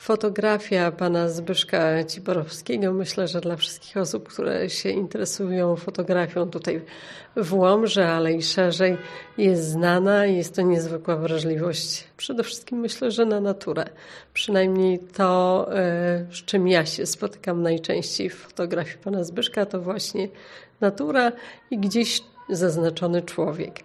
O szczegółach mówi Wicestarosta Łomżyński Maria Dziekońska: